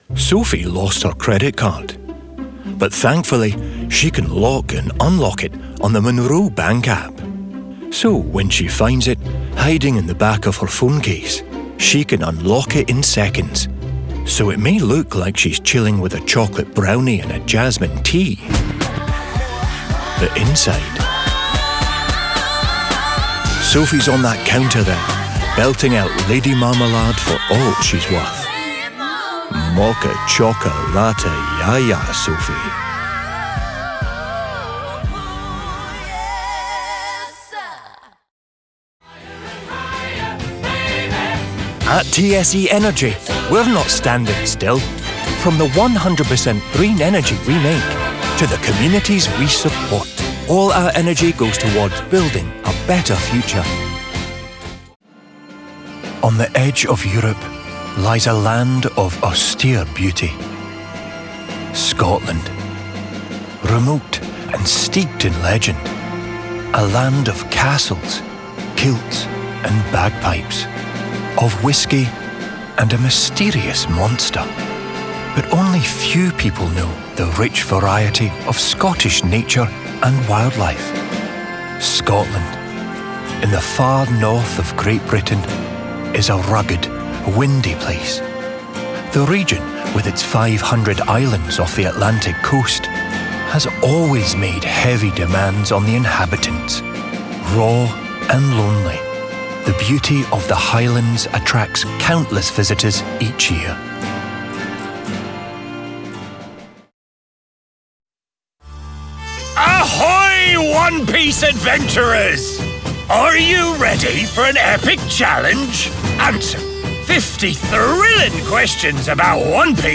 Compilation Showreel
A charming cheeky soft-spoken Scot, with a voice like being hugged by a hot toddy.
Male
Scottish
Gravitas
Smooth